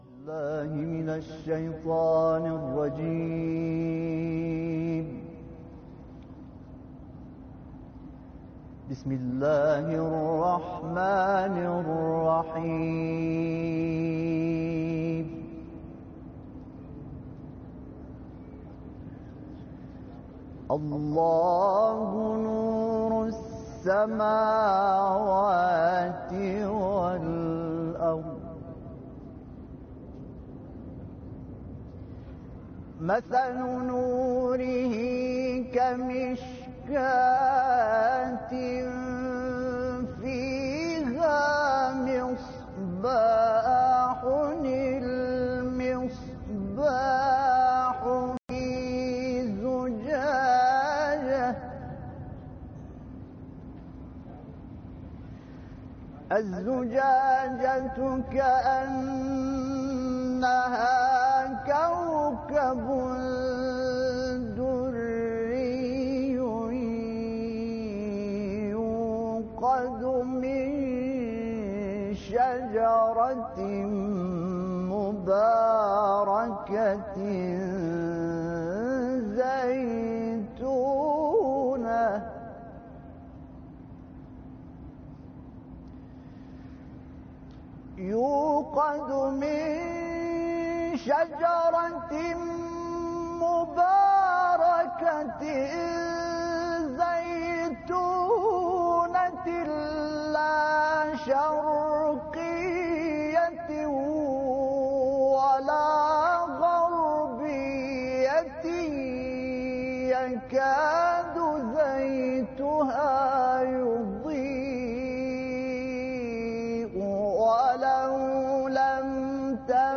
تلاوة من سورة
مرقد الامام الرضا